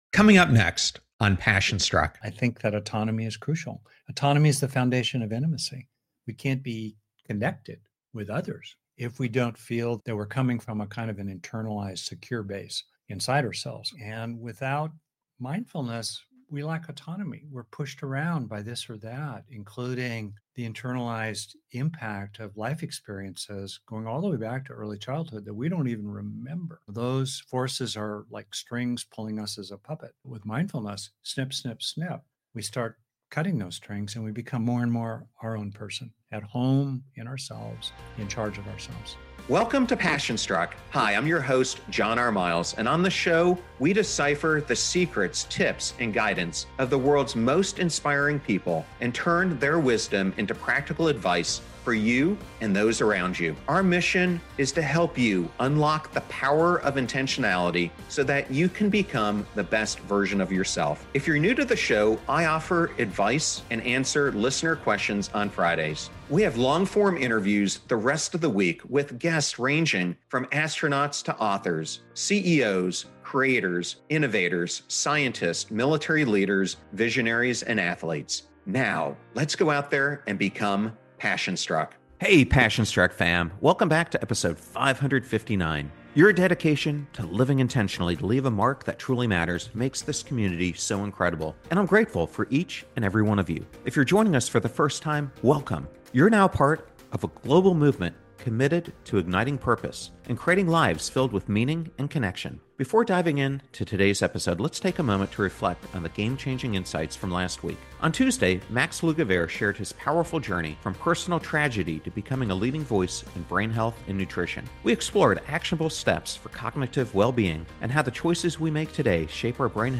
We explore how to harness the brain’s neuroplasticity to cultivate resilience, inner peace, and a sense of purpose. Whether you’re feeling stuck in an invisible cage or simply seeking practical tools to rewire your mind for joy and fulfillment, this conversation will inspire you to embrace your power and live with intention.